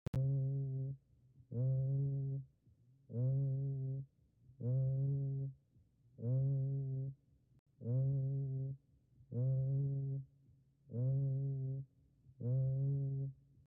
Egofonía. La presencia de aire o líquido en la cavidad pleural produce reflexión de los sonidos.
Se pierden especialmente las frecuencias bajas, bajo 1000 Hz. Así, la voz adquiere un tono característico, tembloroso: voz caprina.
Egofonia_anormal.mp3